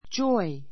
joy 中 A2 dʒɔ́i ヂョ イ 名詞 喜び, うれしさ; 喜びを与 あた えるもの, 喜びの種 We were filled with joy when we heard the news.